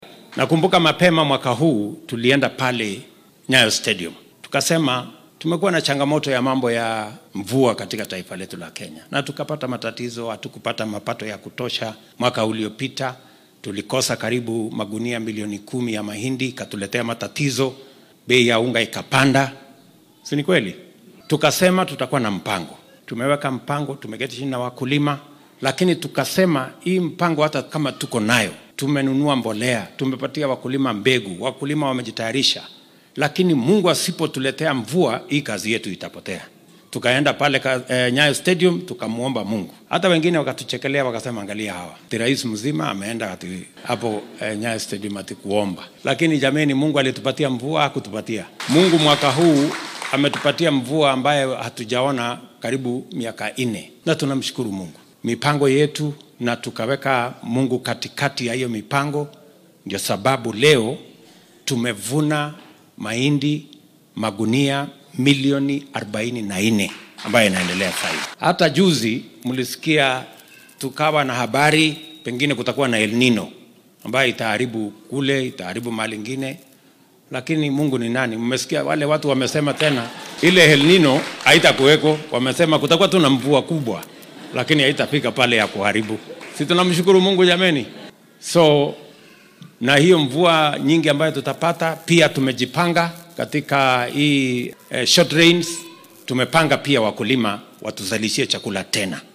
Xilli uu maanta hadal ka jeedinayay kaniisad ku taalla xaafadda Dagoretti ee ismaamulka Nairobi ayuu madaxweyne Ruto sheegay in hoos loo dhigay saadaashii hore marka la eego baaxadda roobabka la rajeynaya.